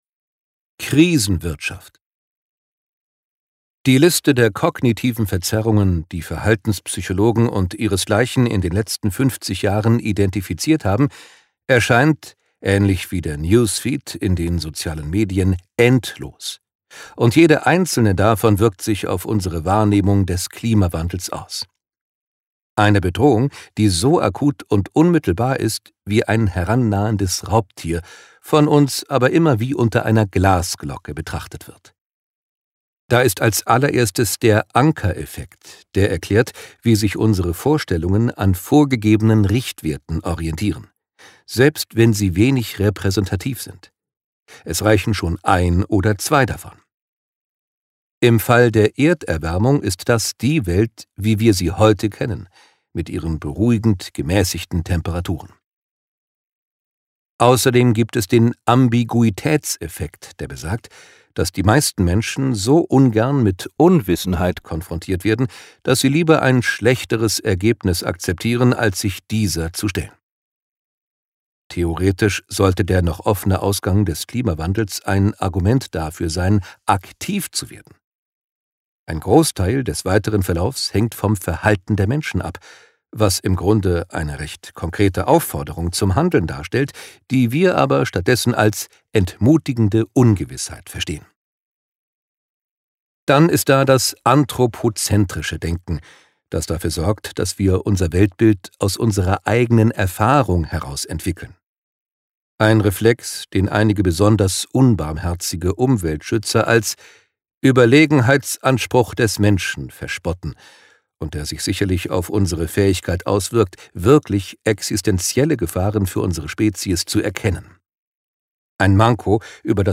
Genre: Lesung.